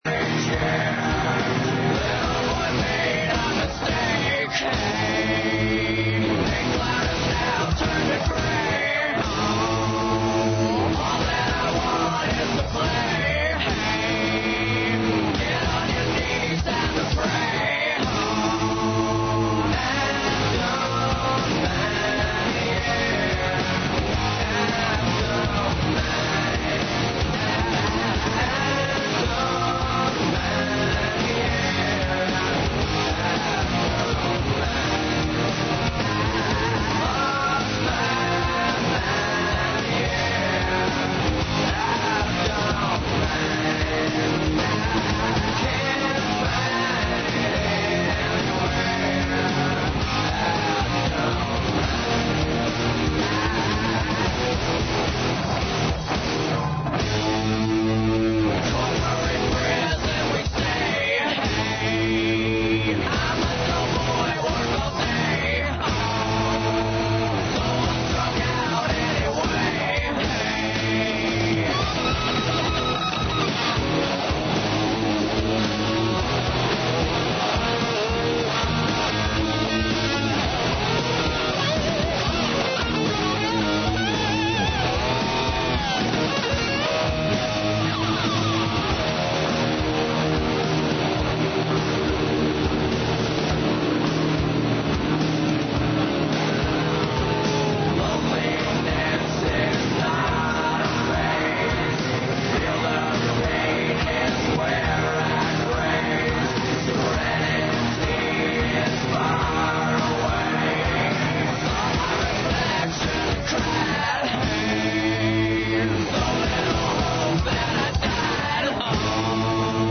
Ноћни програм Београда 202.
Тако некако изгледа и колажни контакт програм ''САМО СРЦЕМ СЕ ДОБРО ВИДИ''.